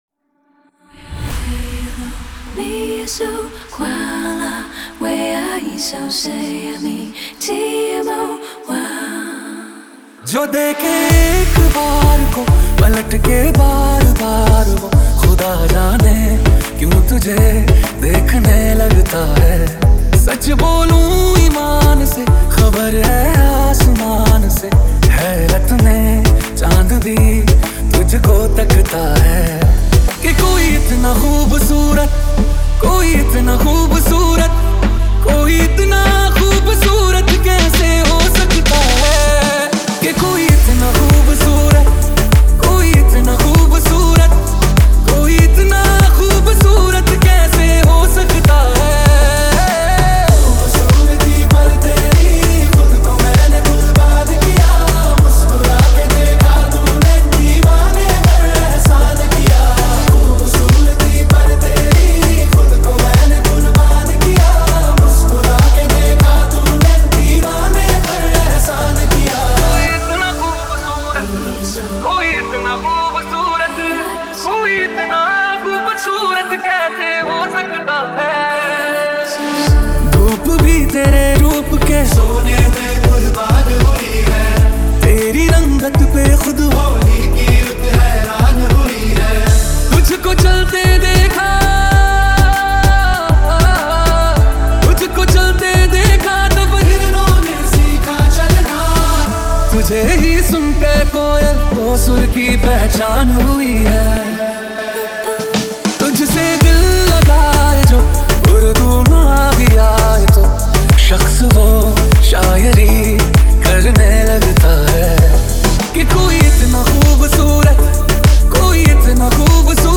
2024 Bollywood Single Remixes Song Name